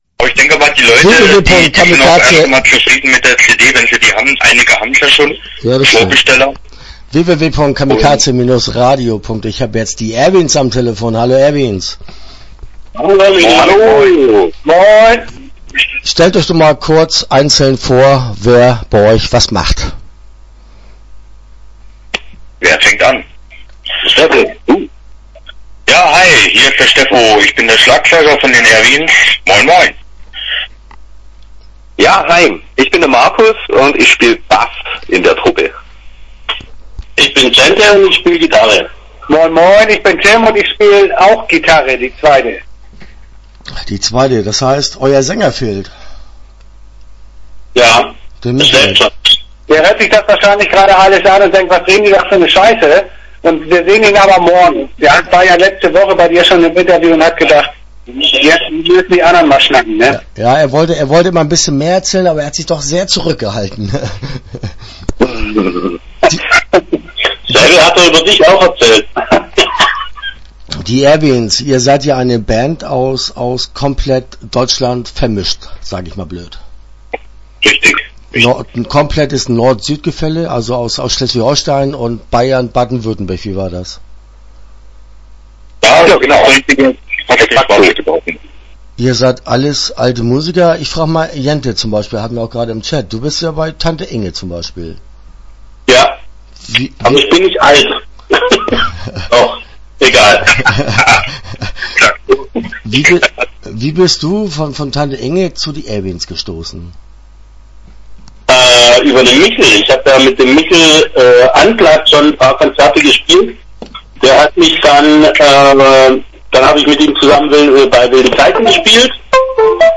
Die Erwins - Interview Teil 1 (14:19)